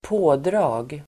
pådrag substantiv, Uttal: [²p'å:dra:g] Böjningar: pådraget, pådrag, pådragen Definition: stor insats av arbetskraft, uppbåd av många människor Exempel: fullt pådrag (at full steam) Sammansättningar: polispådrag (a large number of police)